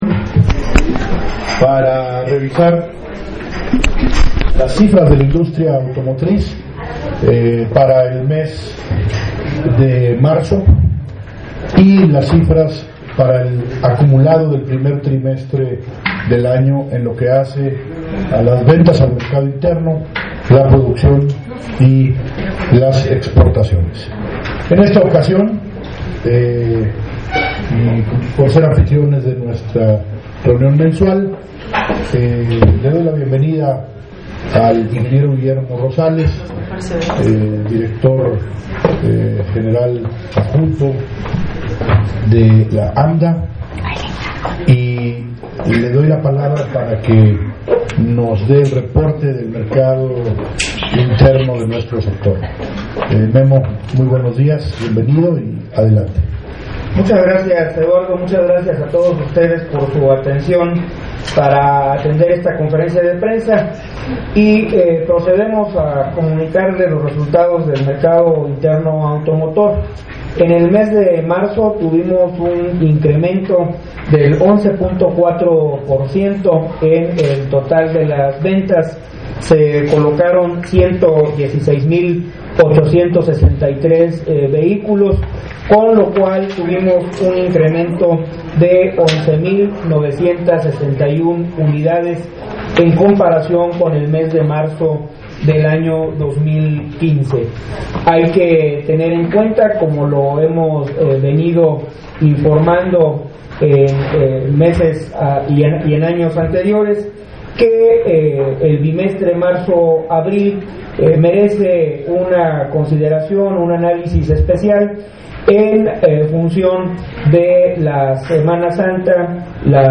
audio de la conferencia